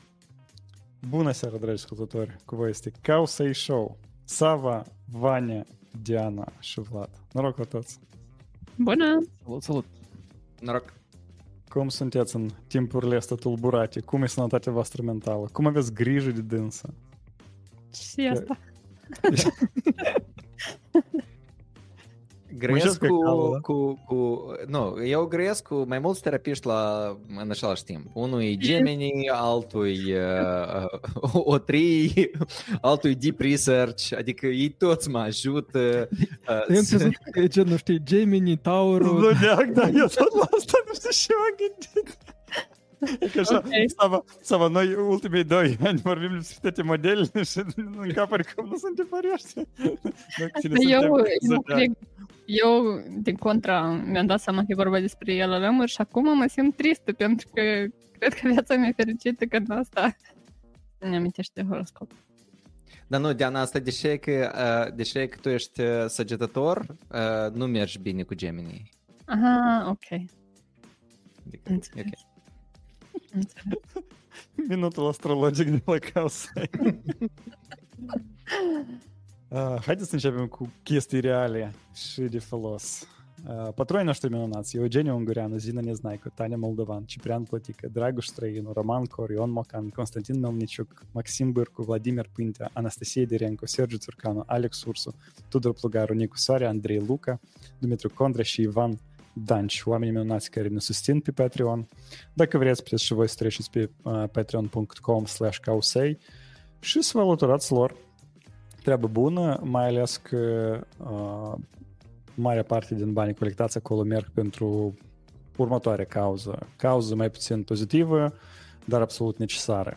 Live #134: Anthropic știe să măsoare; UK știe să decripteze; Altman știe să 🥓 roastuiască February 13th, 2025 Live-ul săptămânal Cowsay Show.